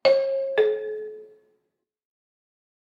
Subway MTA Door Close Chime
announcement bing chime ding door metro mta new-york-city sound effect free sound royalty free Sound Effects